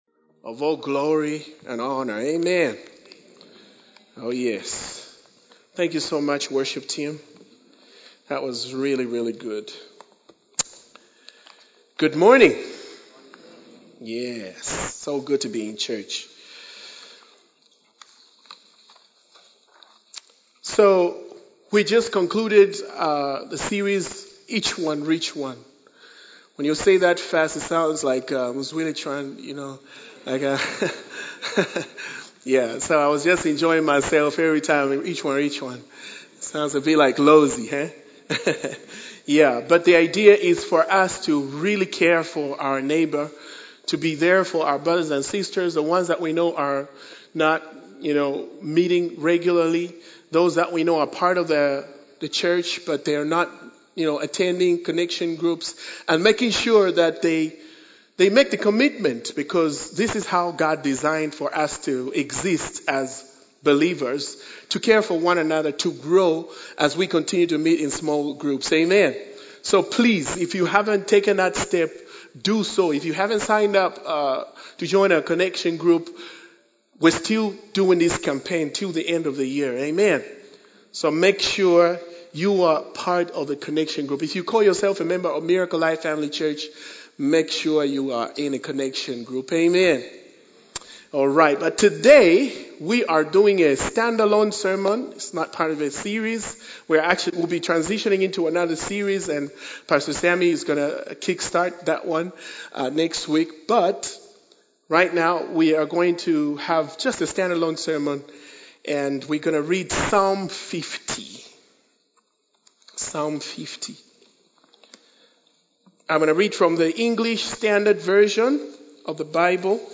A message from the series "Individual Sermons."